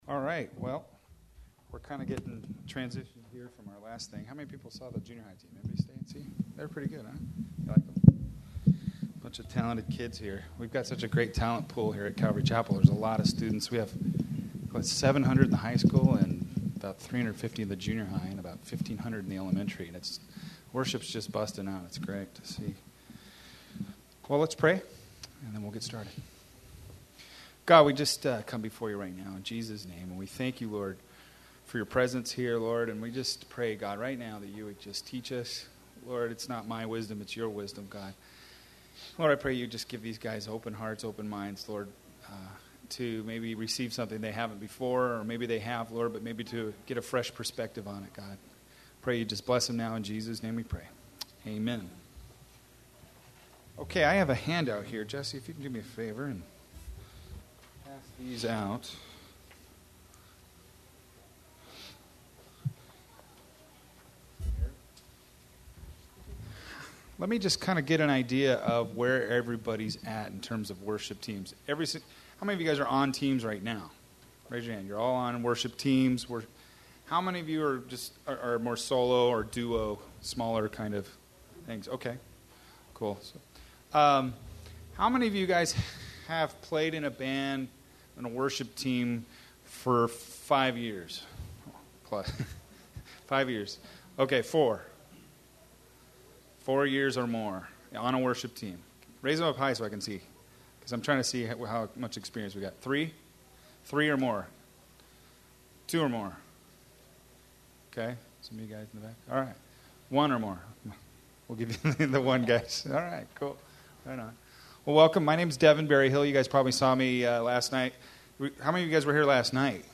Series: 2004 Saturated Youth Worship Conference
Campus: Calvary Chapel Costa Mesa